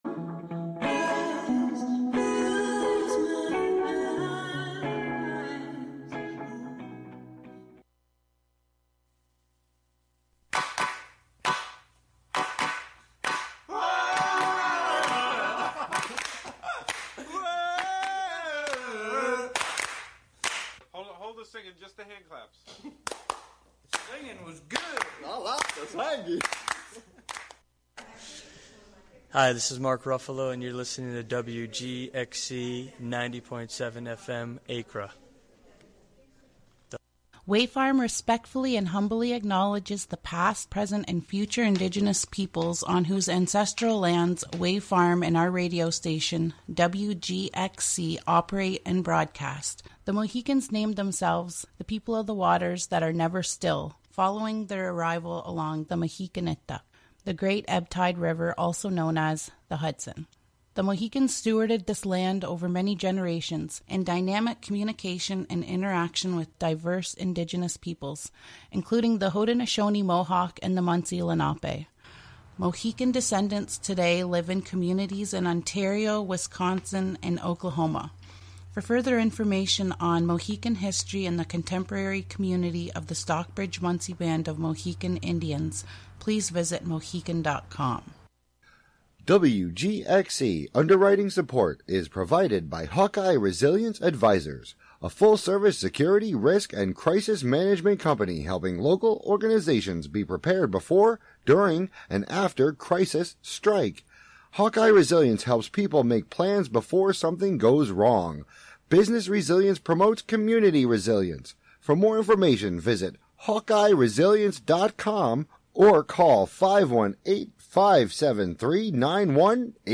"Long Pause" is an invitation to slow down into interstitial realms through sound. With a blend of song, field recording, archival audio, and conversation, the show explores the thin spaces between the ordinary and sacred, human and nonhuman, particular and universal, and visible and invisible, through a different sonic theme and/or medium each month.